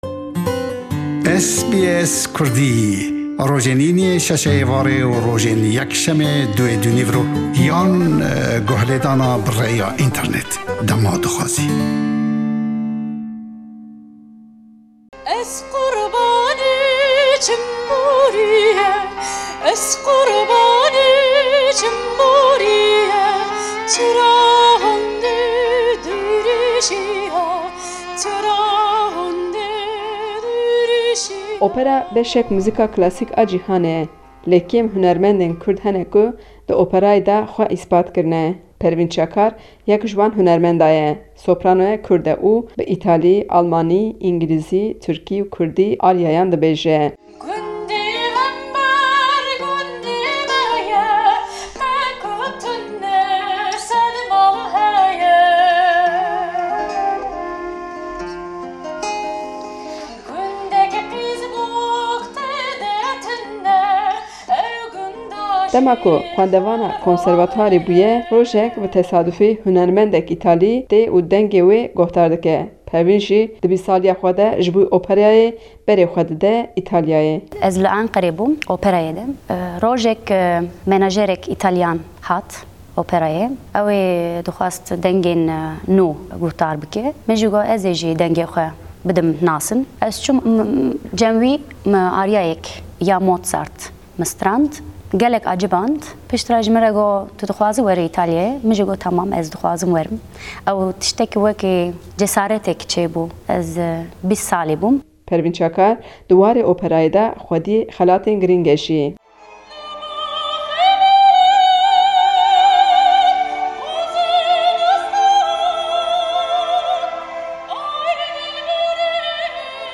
Kurte hevpeyvîneka